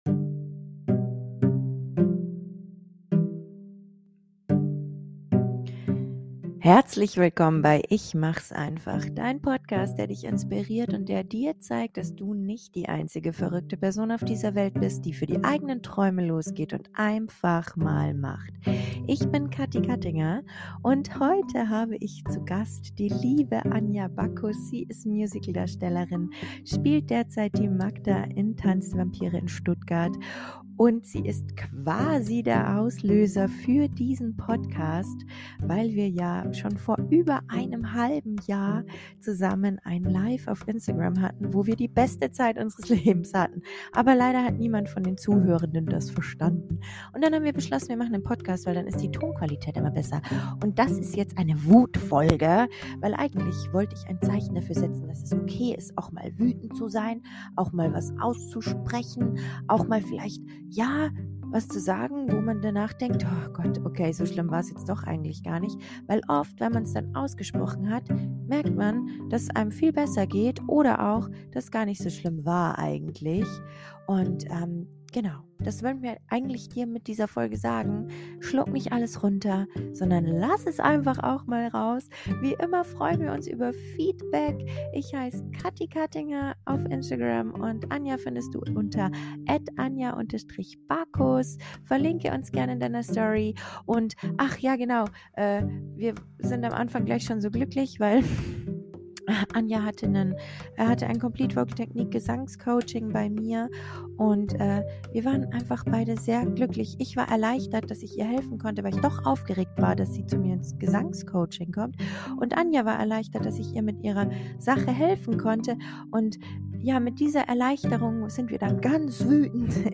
In dieser Folge sind wir WÜTEND!
Gut, wir lachen auch ziemlich viel und erzählen viel Quatsch, aber dazwischen schaffen wir es doch auch mal wütend zu sein.